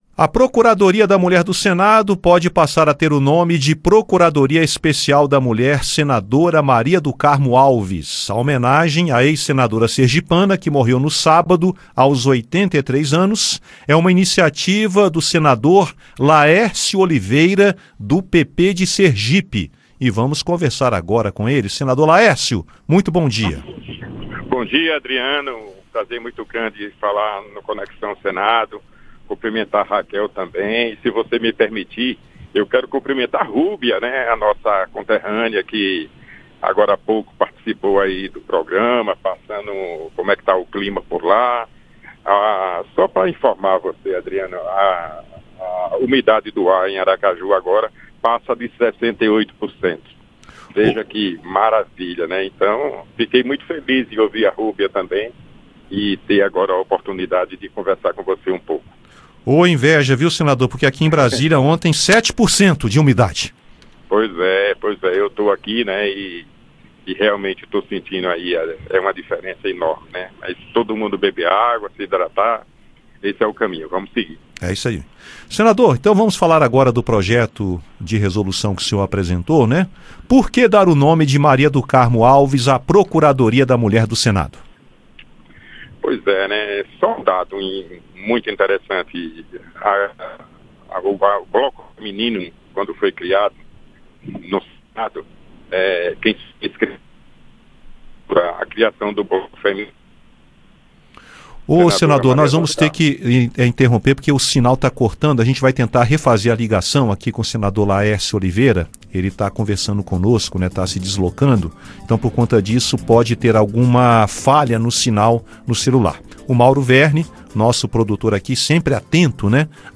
O senador Laércio fala sobre o projeto e as expectativas de tramitação no Senado.